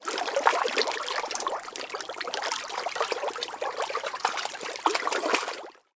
Звук: Рыбы-поверхностники плещутся в воде